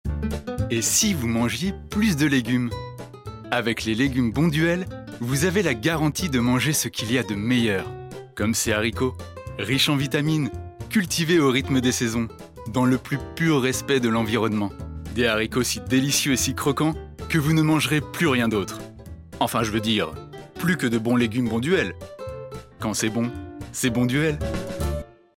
Pub Bonduelle - Enregistrée aux locaux d'Europe 1.
- Baryton